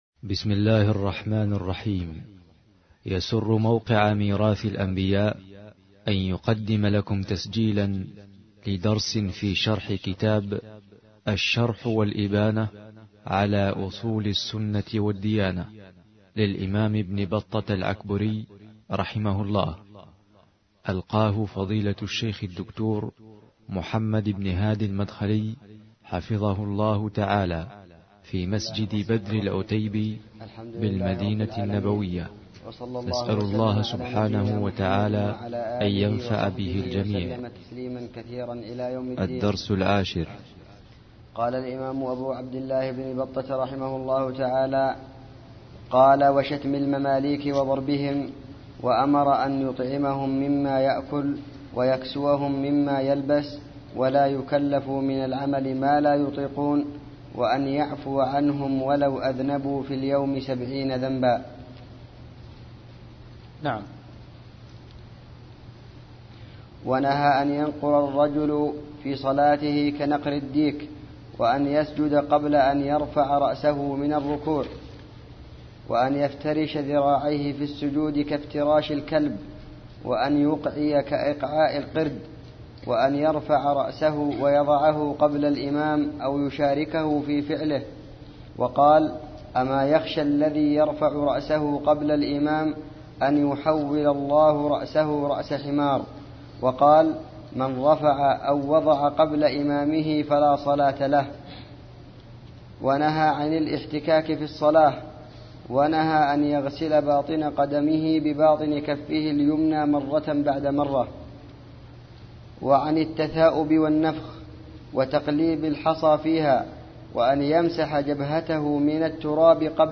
الدرس العاشر